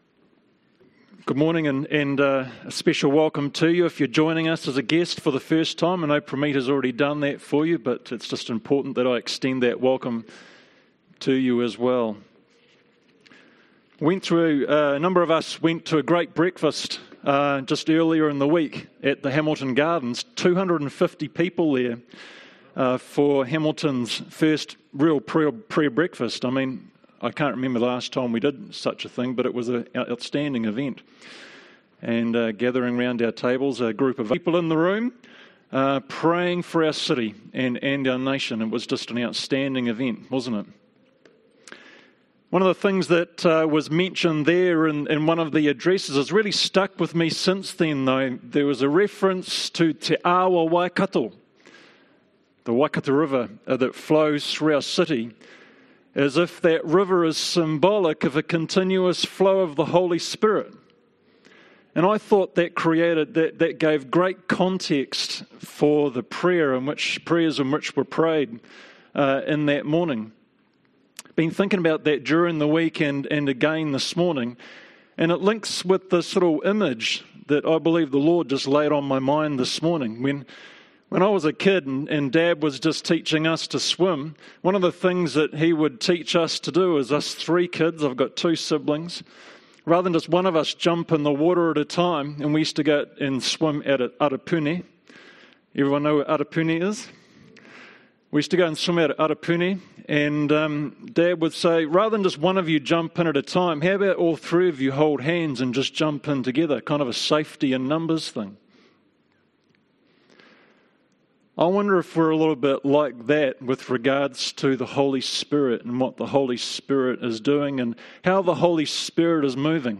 Love One Another - Fairfield Baptist Church
Sermon